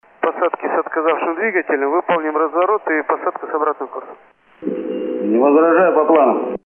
Отказавший движок
samolet.mp3